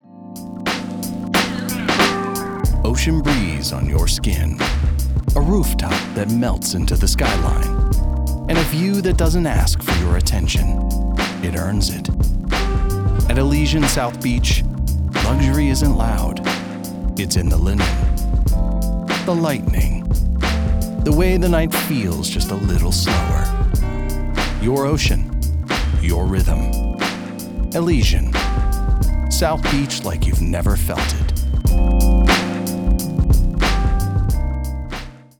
Elegant · Smooth · Luxury
Polished, upscale read for luxury hospitality and real estate brands.